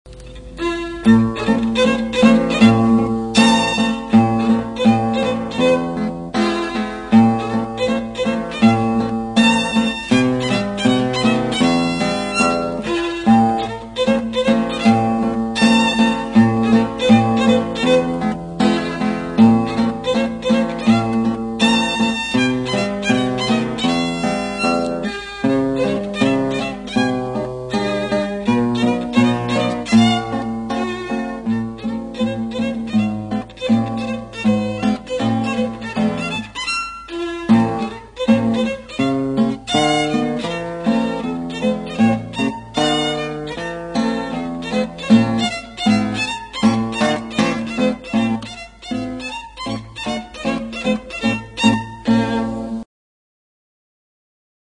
They are mostly live extracts from public performances.
centone1.mp3 (live extract)
for violin and guitar